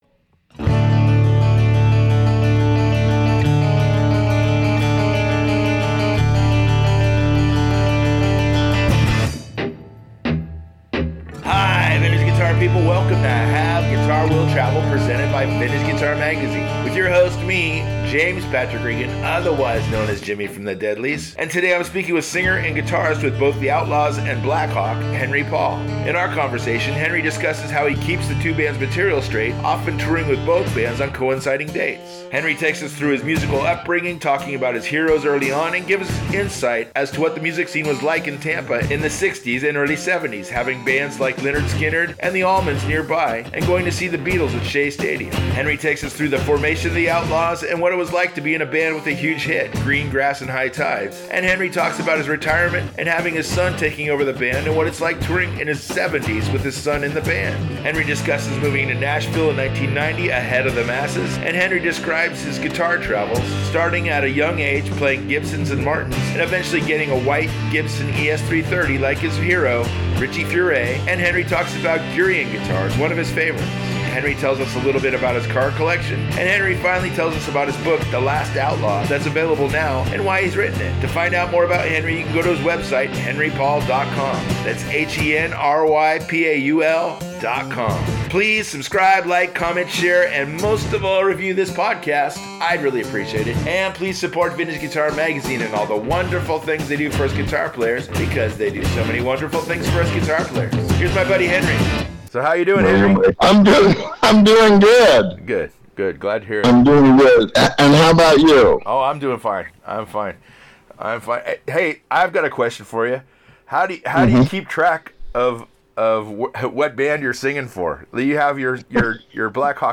speaks with singer and guitarist with both Outlaws and Blackhawk, Henry Paul.